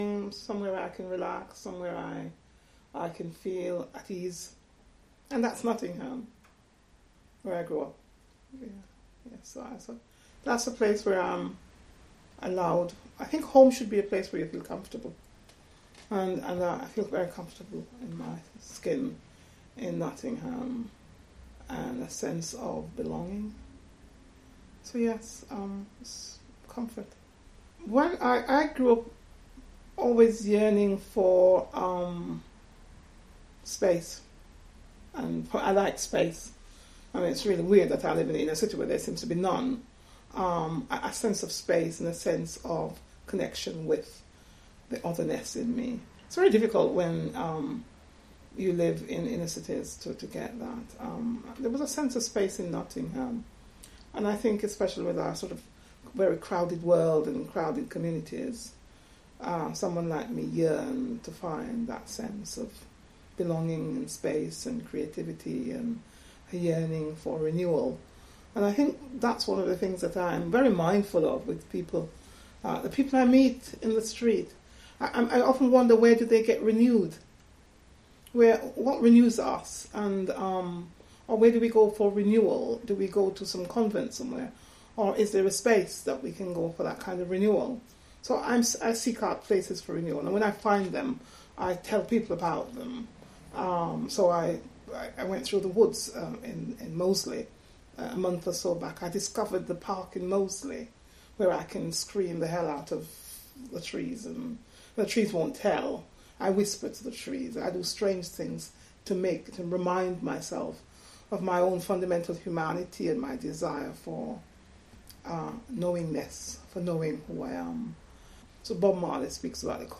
We worked with photographer Vanley Burke and older African-Caribbean women to create Home.